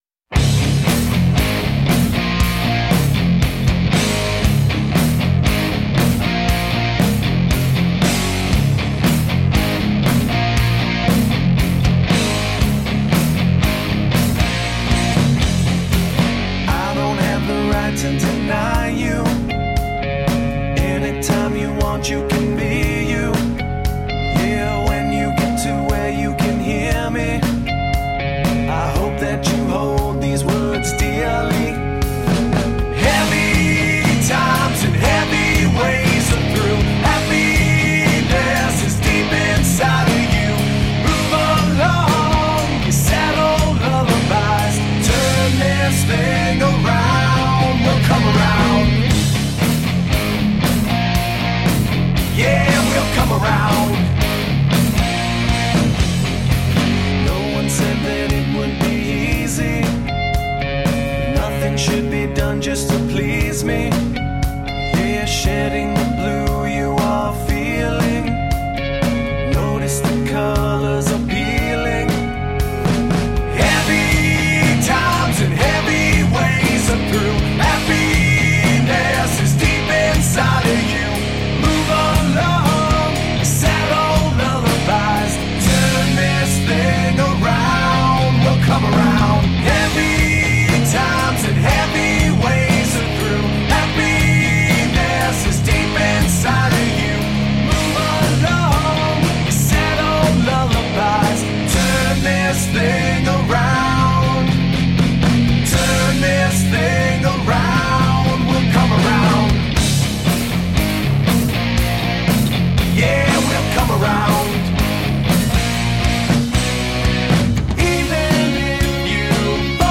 Where modern rock meets classic rock.
Recording and Mixed in San Francisco's
Tagged as: Alt Rock, Hard Rock